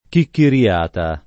chicchiriata [ kikkiri- # ta ] s. f.